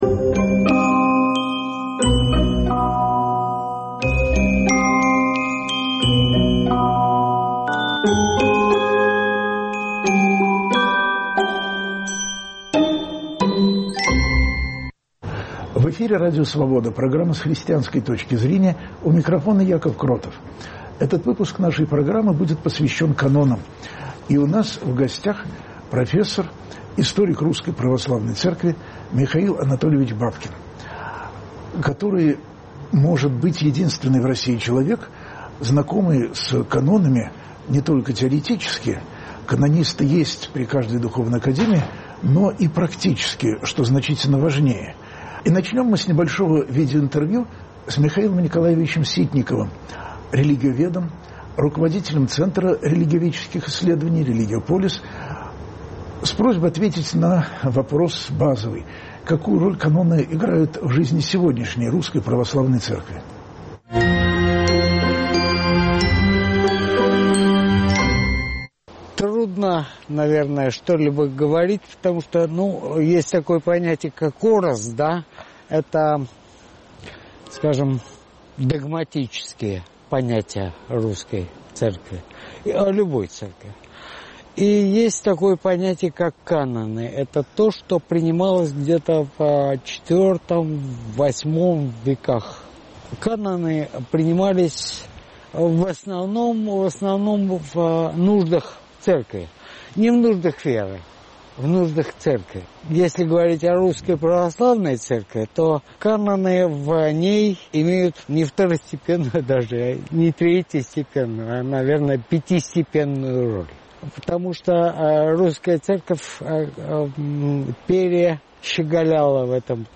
разговор